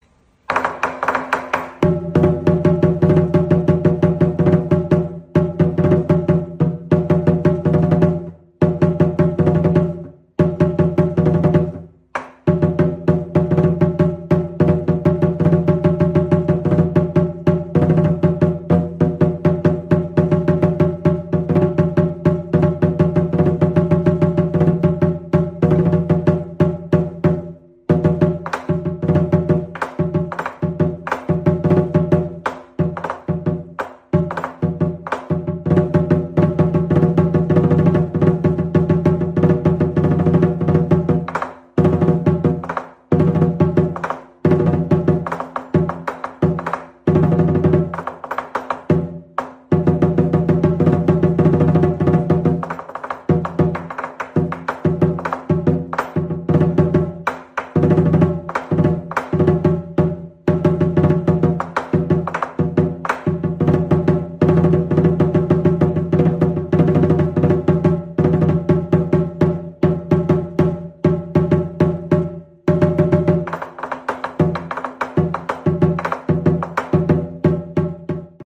Tiếng đánh trống Múa Lân, Lễ hội (chỉ có tiếng trống)
Thể loại: Tiếng động
Description: Âm thanh trống Múa Lân vang dội, rộn ràng như nhịp tim của lễ hội, mang lại cảm giác phấn khích và sôi động. Tiếng trống hội, trống lân, trống chiến, trống khai hội dồn dập, ngân vang với âm bass mạnh mẽ, lan tỏa không khí náo nhiệt.
tieng-danh-trong-mua-lan-le-hoi-chi-co-tieng-trong-www_tiengdong_com.mp3